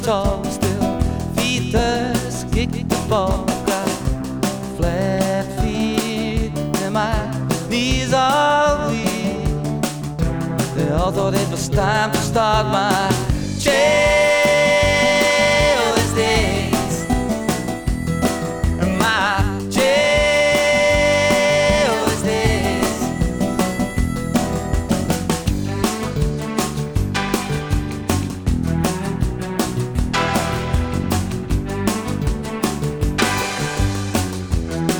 Pop Pop Rock Alternative New Wave Rock Adult Alternative
Жанр: Поп музыка / Рок / Альтернатива